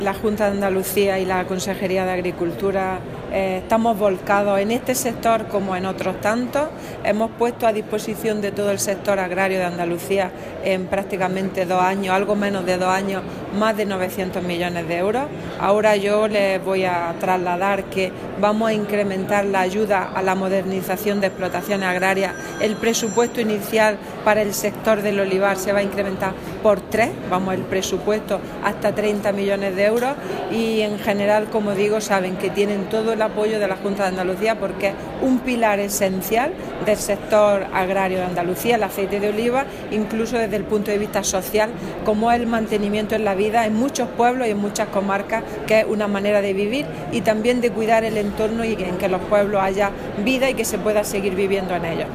Declaraciones consejera apoyo al sector